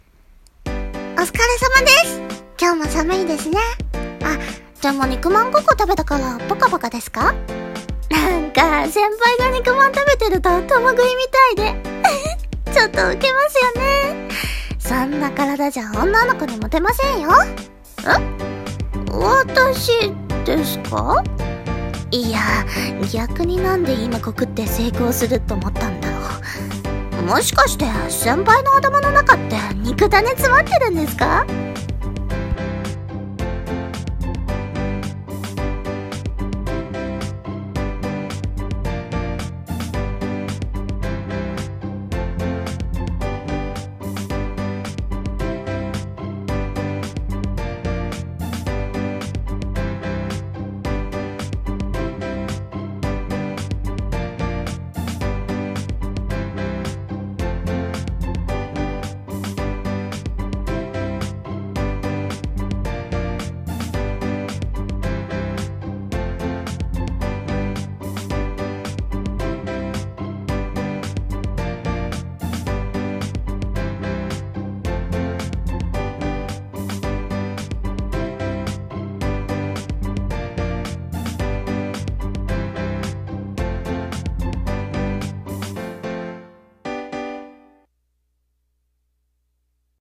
【セリフ】冬は後輩が冷たい。【サディスト】